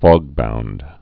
(fôgbound, fŏg-)